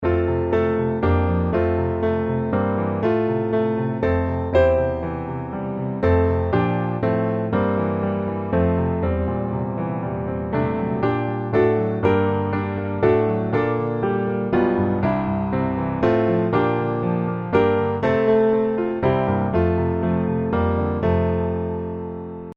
F Major